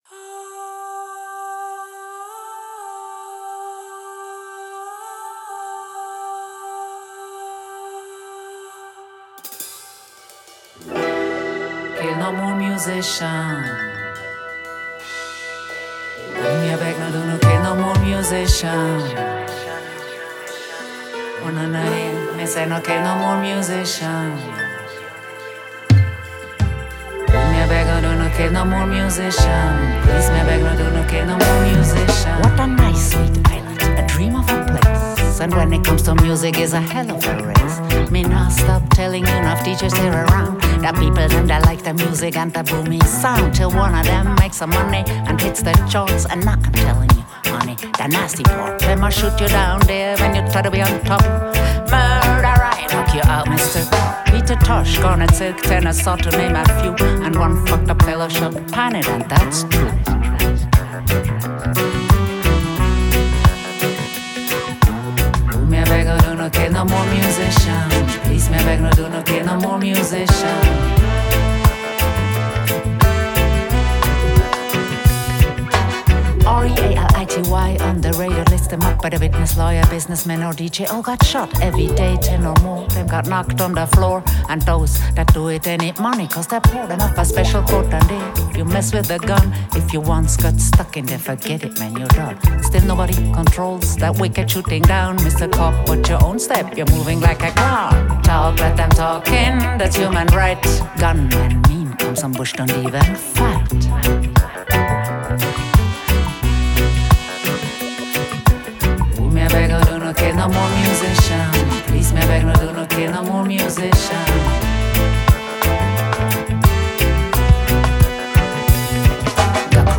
bassist, vocalist, and music composer.
Genre: Reggae